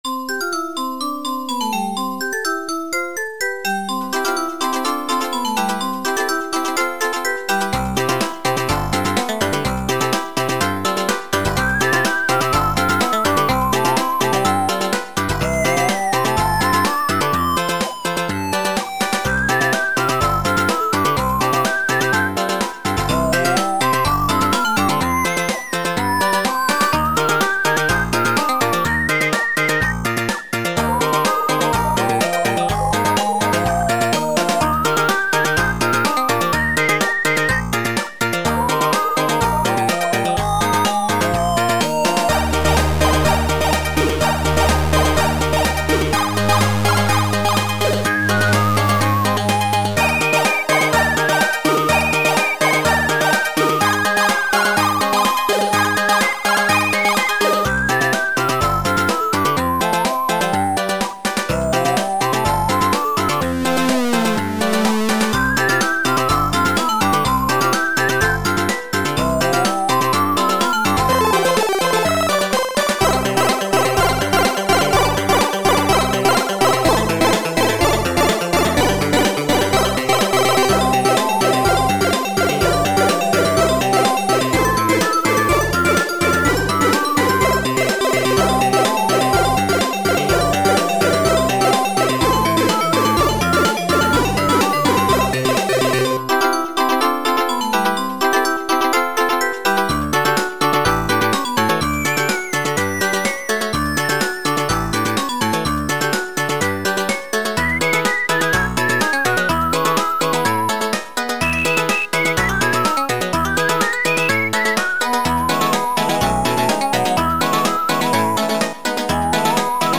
Sound Format: Soundmon 2